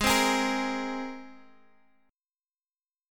Abadd9 chord